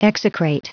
Prononciation du mot execrate en anglais (fichier audio)
Prononciation du mot : execrate